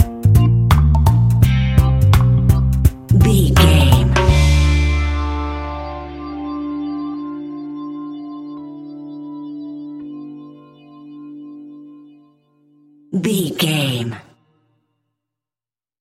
Classic reggae music with that skank bounce reggae feeling.
Aeolian/Minor
instrumentals
laid back
chilled
off beat
drums
skank guitar
hammond organ
transistor guitar
percussion
horns